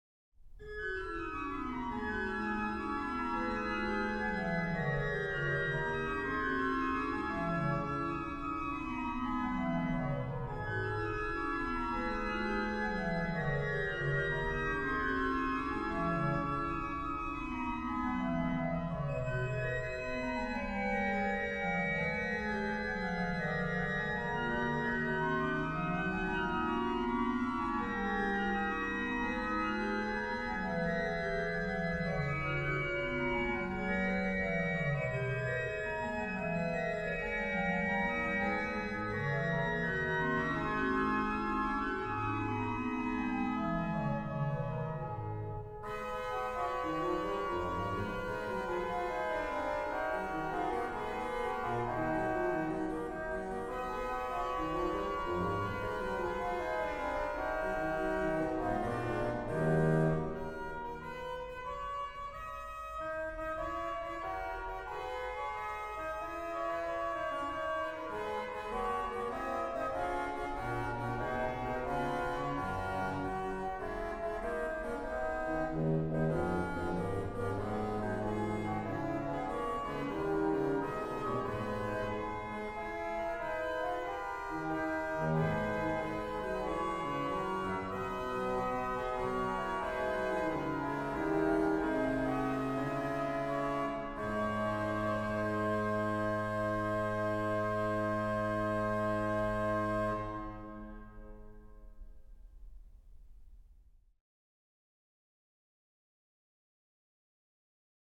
Registration   Pos: Rfl8, Oct2
m. 16: BW: Fl4, Dulc8